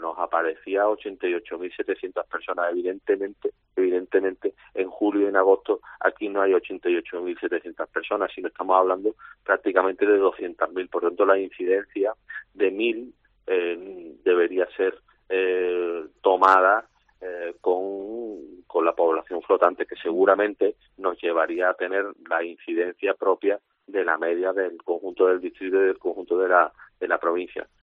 Germán Beardo, alcalde de El Puerto, en COPE